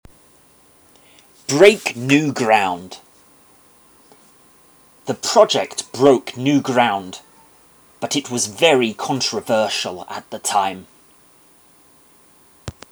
この ground には new や fresh といった形容詞が使われ、新しい分野を切り開く、という意味となります。 マンツーマンのレッスン担当の英語ネイティブによる発音は下記のリンクをクリックしてください。